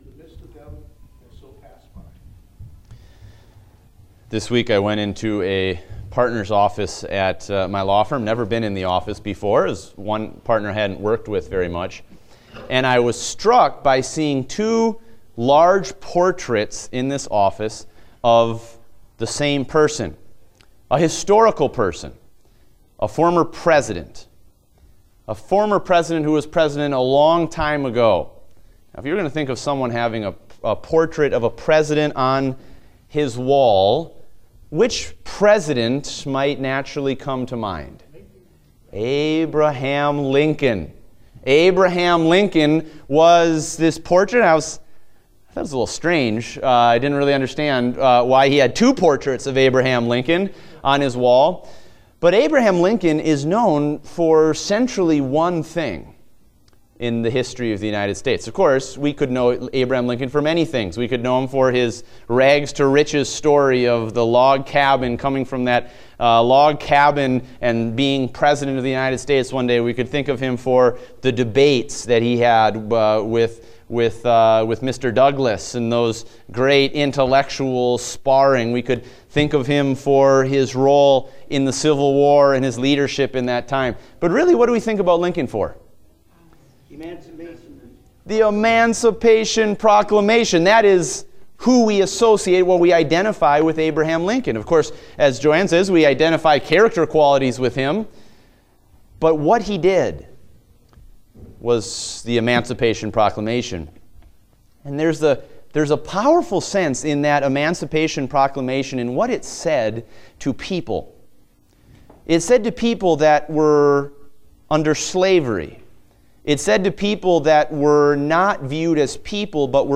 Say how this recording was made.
Date: October 23, 2016 (Adult Sunday School)